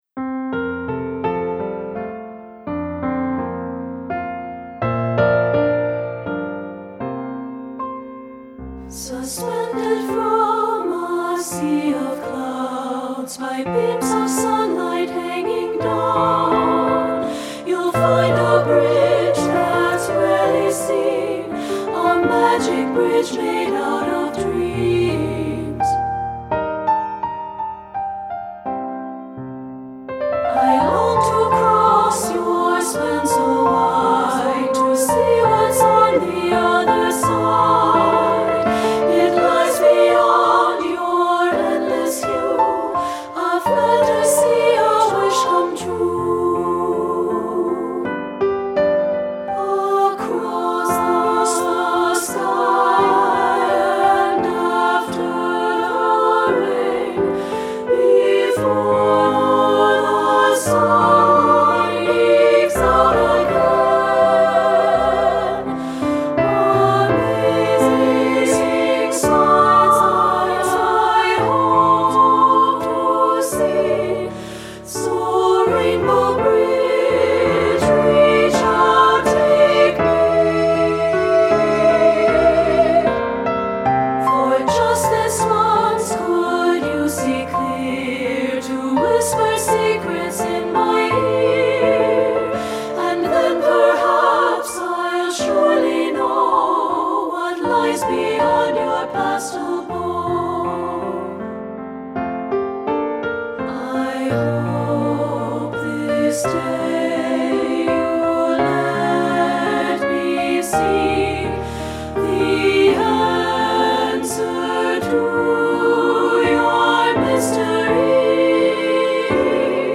• Soprano 1
• Soprano 2
• Alto
• Piano
Studio Recording
flowing melodic lines and colorful harmonies
Ensemble: Treble Chorus
Accompanied: Accompanied Chorus